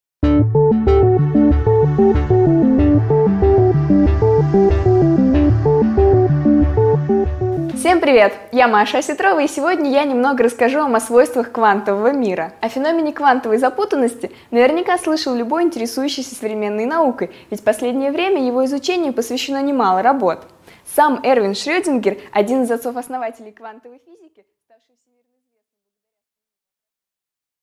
Аудиокнига 5 минут О квантовой случайности | Библиотека аудиокниг